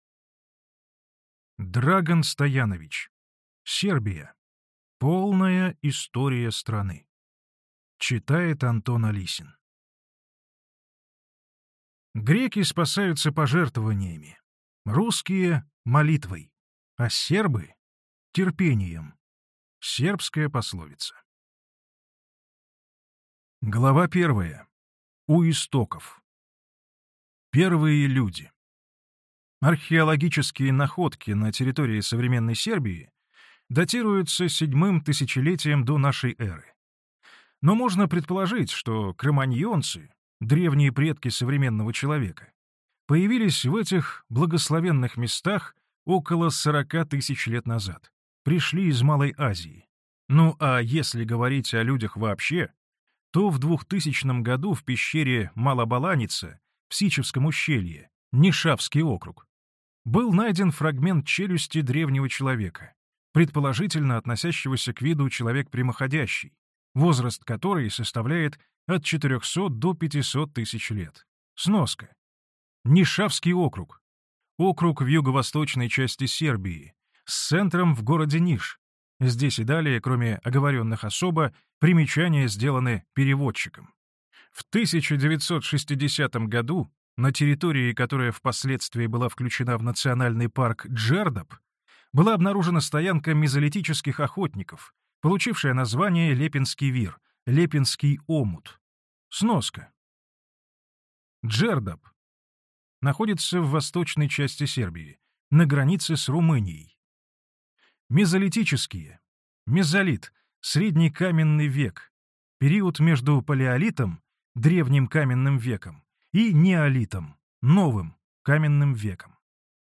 Аудиокнига Сербия. Полная история страны | Библиотека аудиокниг